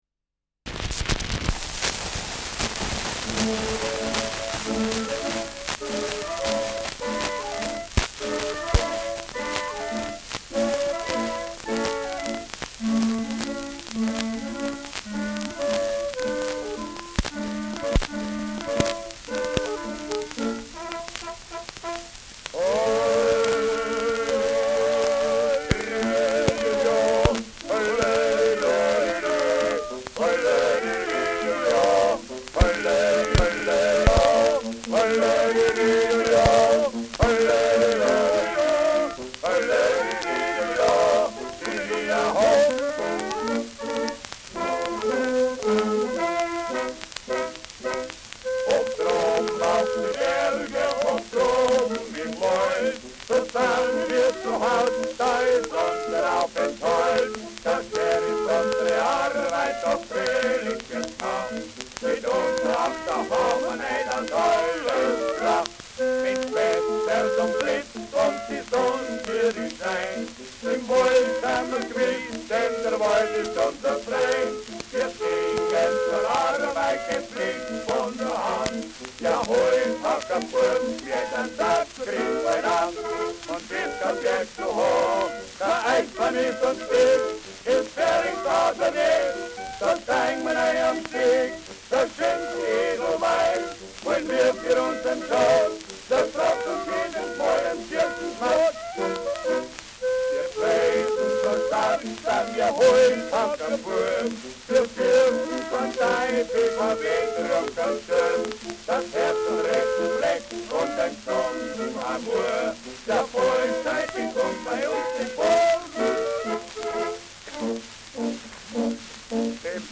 Schellackplatte
[Nürnberg] (Aufnahmeort)